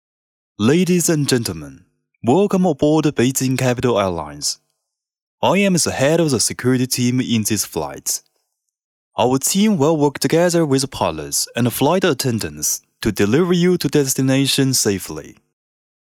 飞机播报